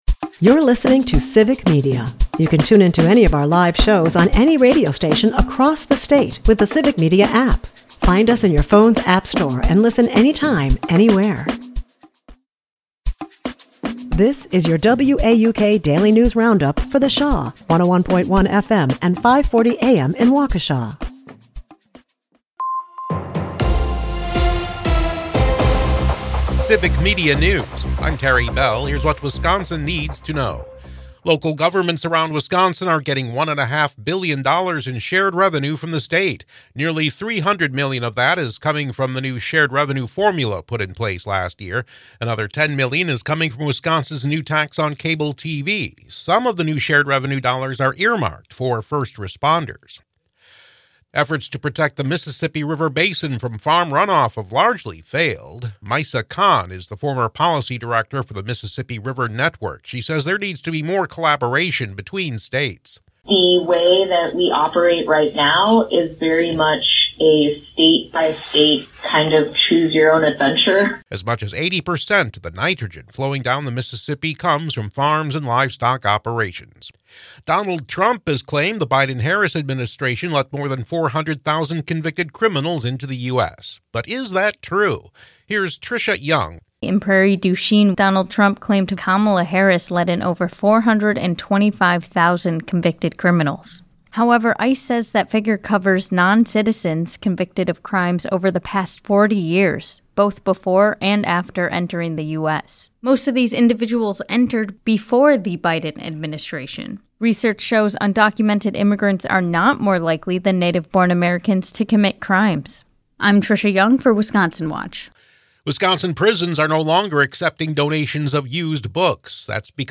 wauk news